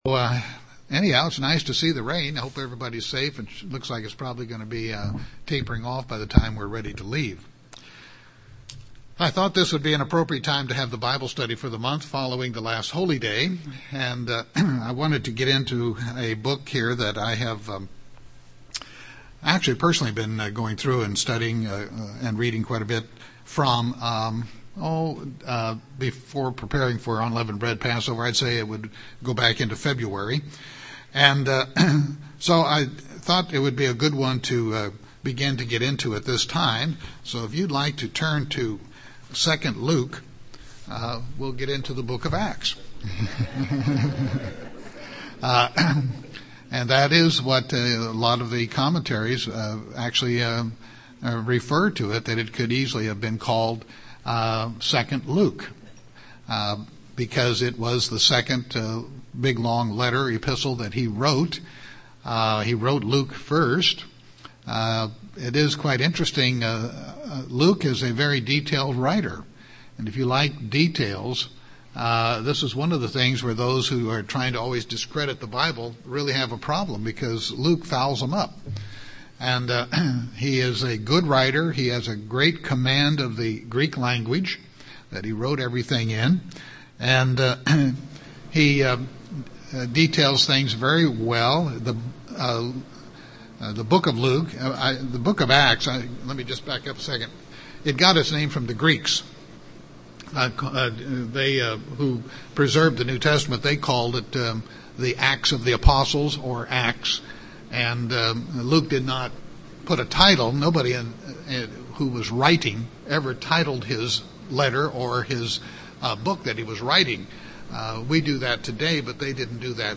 4-14-12  Bible Study.mp3